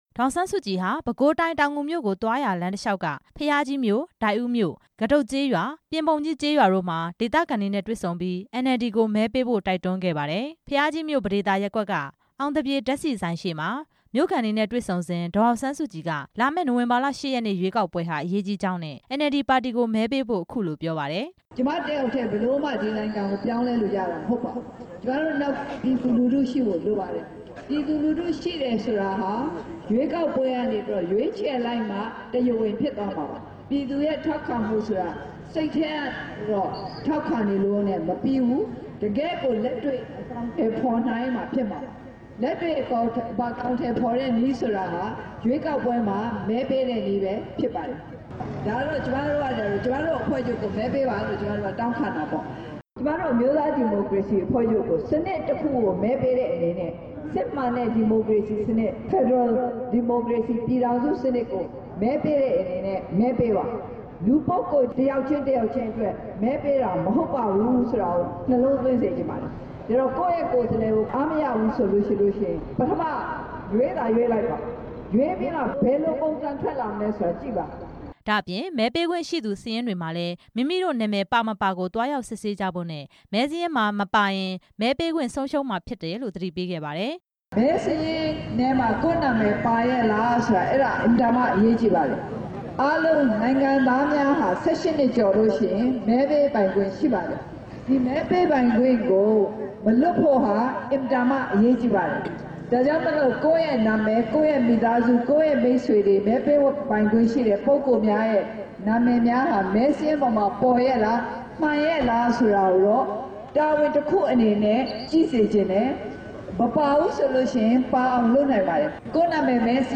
NLD ပါတီကို မဲပေးကြဖို့ တောင်းဆိုနေတာဟာ လူပုဂ္ဂိုလ်တစ်ဦးတစ်ယောက်အတွက် ပြောနေတာ မဟုတ်ပဲ NLD ပါတီအနေနဲ့ အစိုးရဖွဲ့လိုတာကြောင့် တောင်းဆိုနေတာသာဖြစ်ကြောင်း ပဲခူးတိုင်းဒေသကြီး ဒိုက်ဦးမြို့ မြို့မဈေးရှေ့မှာ ဒီနေ့ မနက်ပိုင်းက ဒေသခံတွေနဲ့ တွေ့ဆုံစဉ် ဒေါ်အောင်ဆန်းစုကြည်က ပြောကြားလိုက်တာပါ။